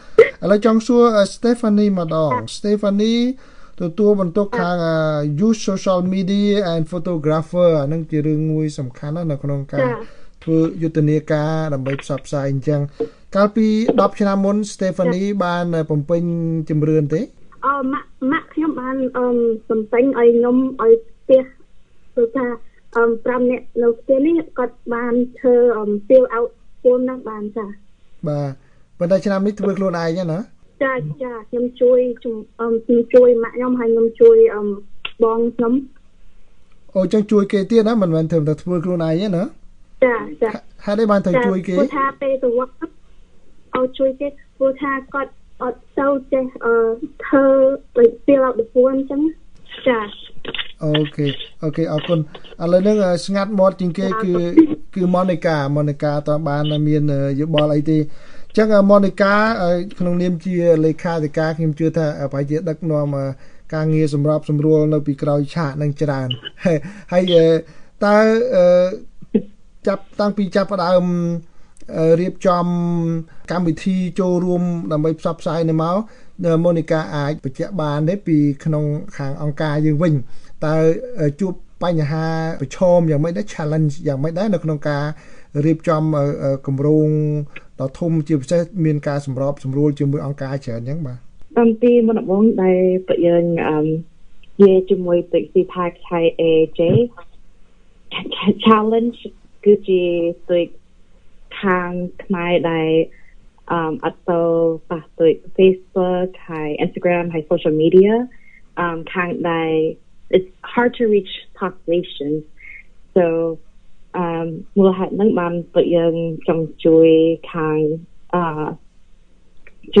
បទសម្ភាសន៍ VOA៖ សកម្មជនខ្មែររួមគ្នានឹងសហគមន៍អាស៊ីនៅរដ្ឋចចារជំរុញពលរដ្ឋបំពេញជំរឿន២០២០